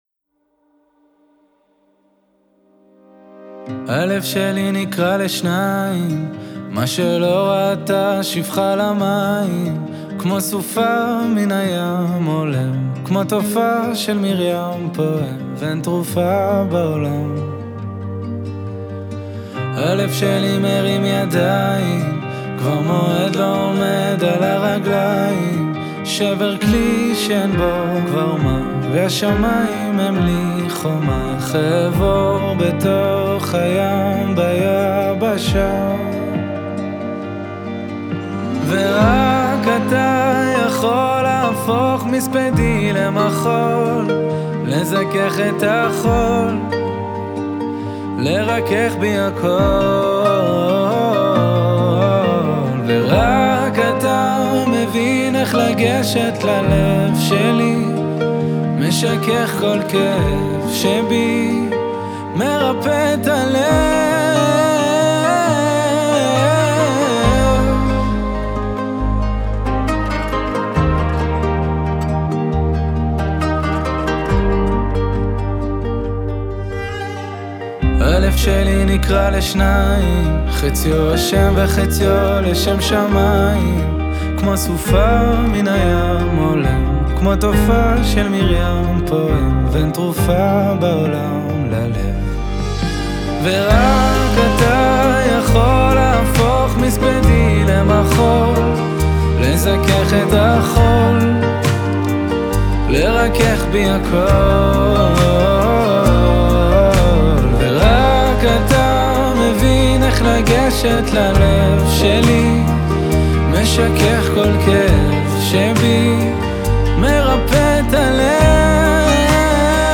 יש צליל אחר, קסום, אתני ושונה
הקמנצ׳ה